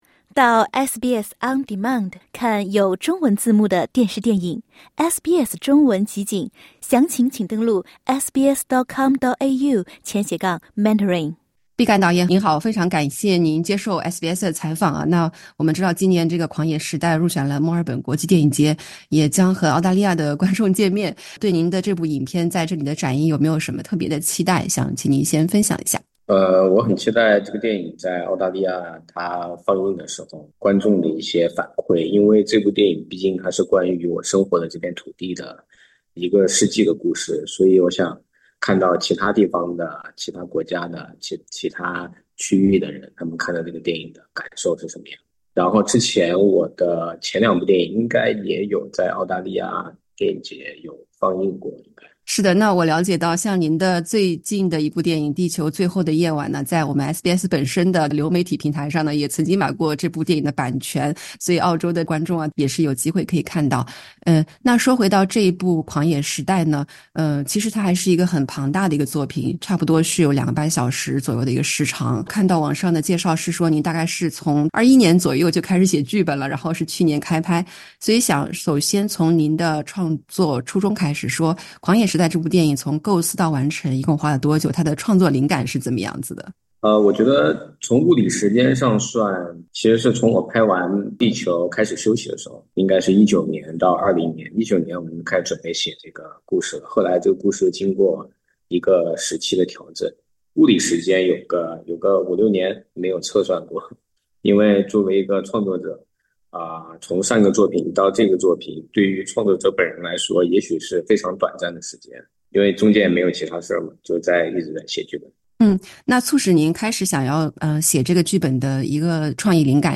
【专访】毕赣谈《狂野时代》：一部关于成长之地的百年虚构编年史